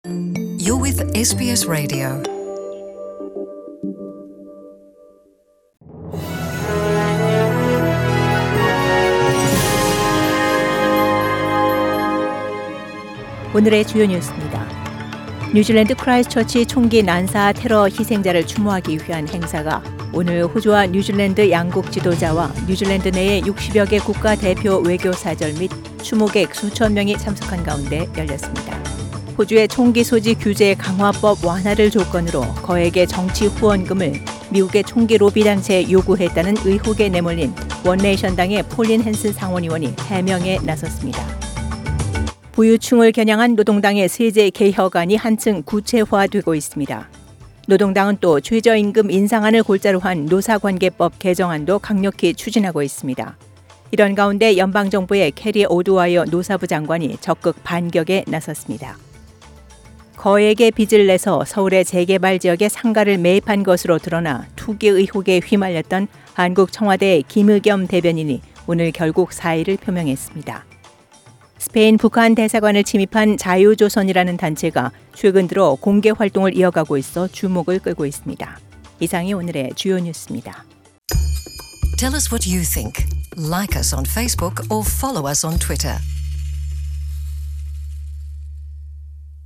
SBS Radio Korean News Bulletin Source: SBS Korean program